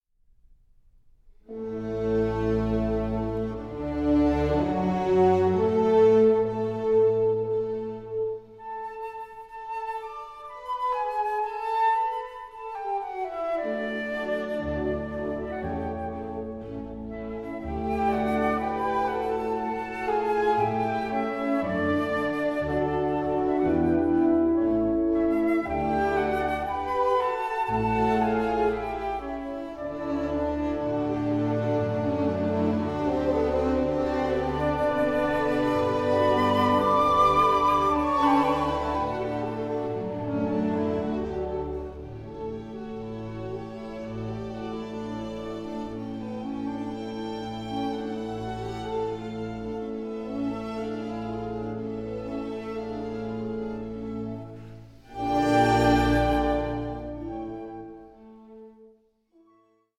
Concerto for Flute and orchestra No.1 in G major